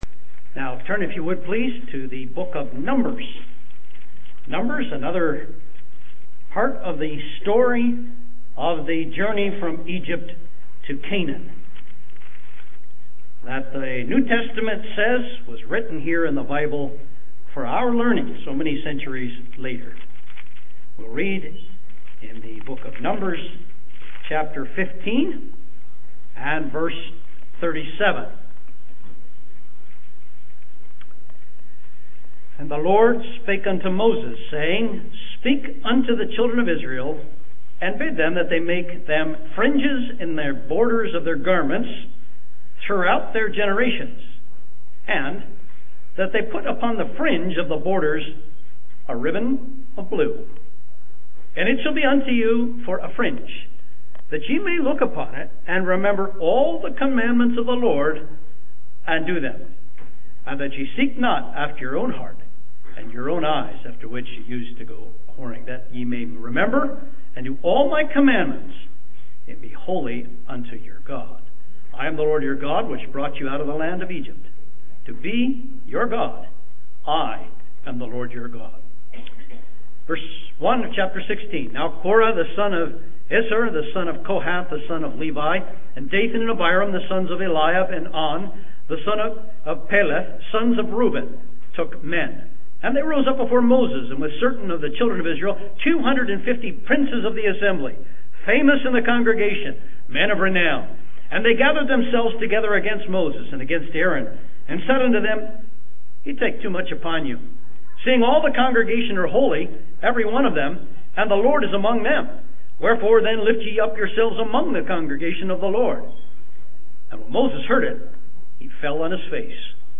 2019 PGH Annual Conference